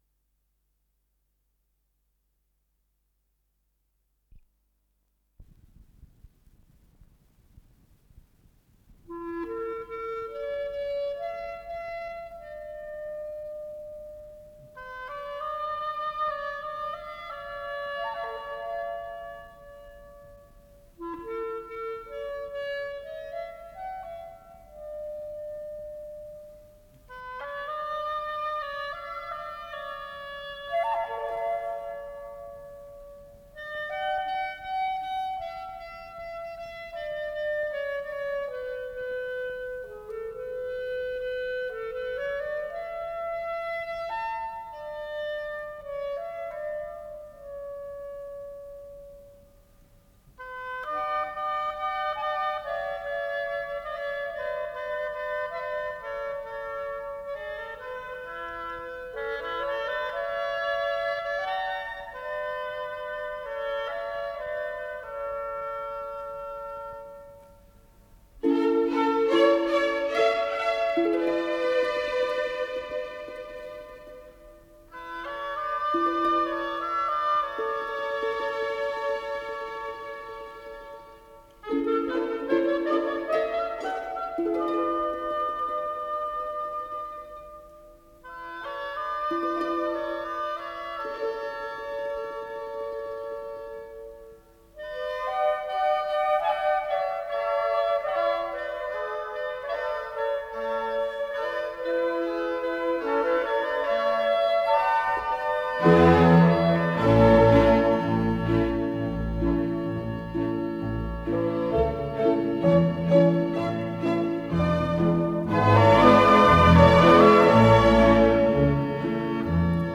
Исполнитель: Большой симфонический оркестр Всесоюзного радио и Центрального телевидения
Для симфонического оркестра, ля мажор